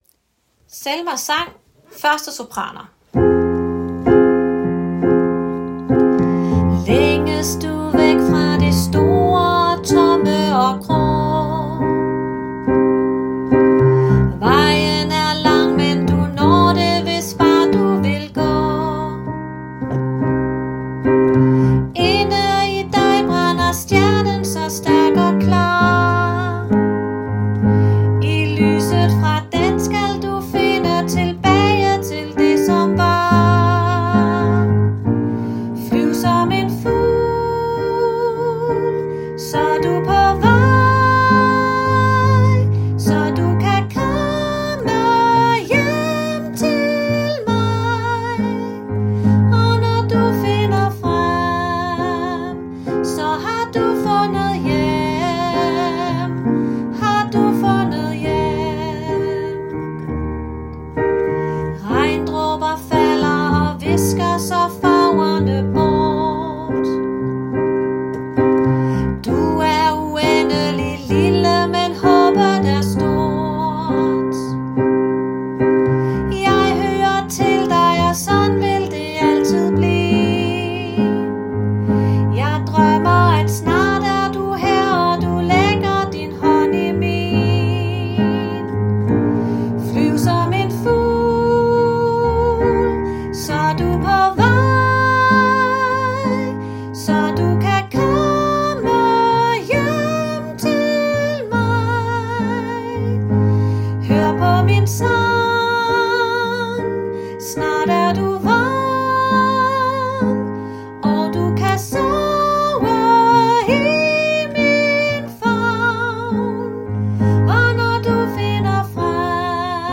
Selmas sang – 1. sopran
Selmas_sang_1.-Sopran.m4a